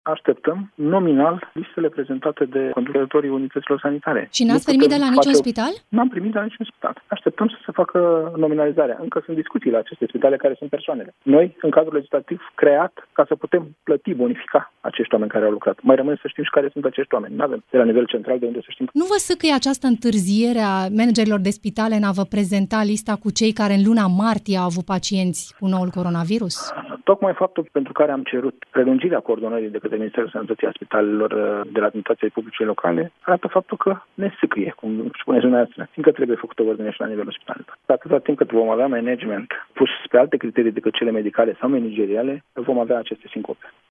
Ministrul Sănătăţii a declarat la Europa FM că nu a primit de la niciun spital lista cu numele celor care se califică pentru plata stimulentului de 2500 de lei brut.